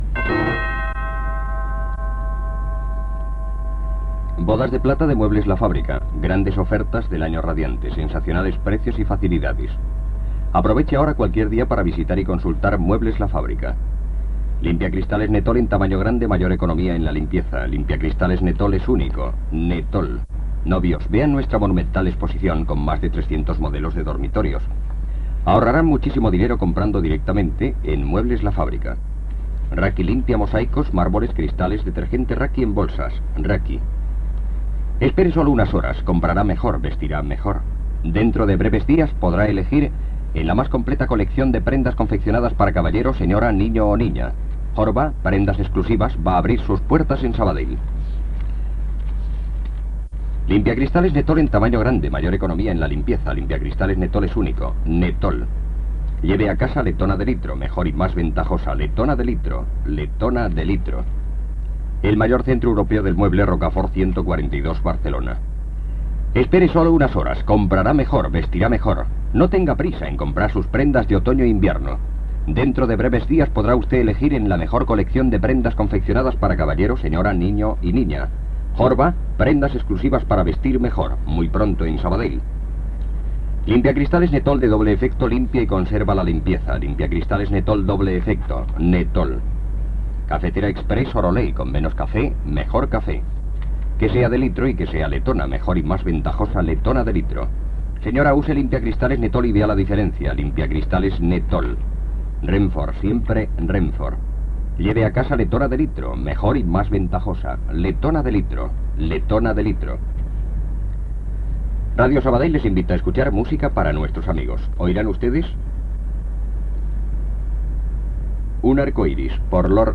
Publicitat i presentació del primer tema dedicat del programa
Musical